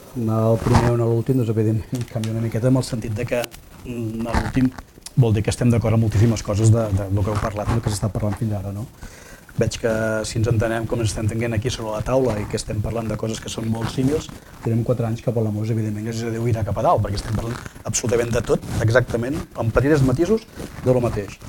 El debat electoral de Palamós i Sant Joan 2019 s’ha emès a Ràdio Capital aquest dijous al vespre amb la presència de tots els alcaldables de la vila palamosina, tret del Partit Popular, que ha declinat la invitació.